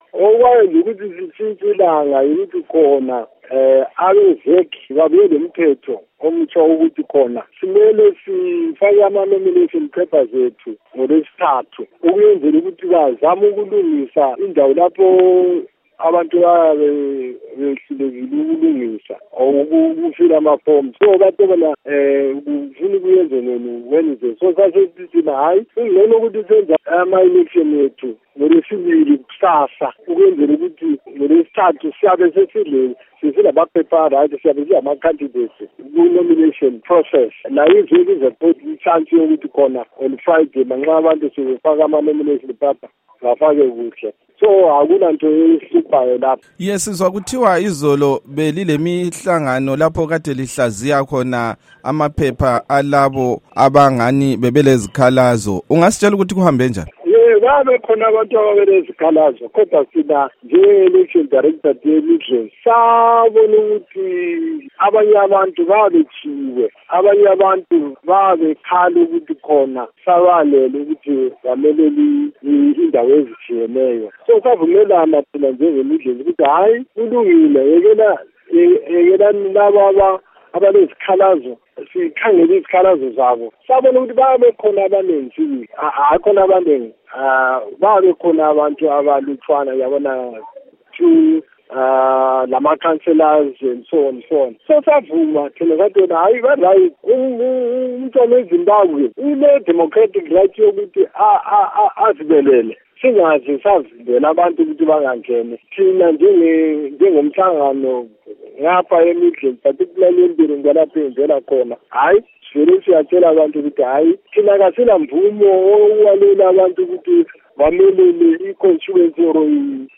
ingxoxo lomnu. rugare gumbo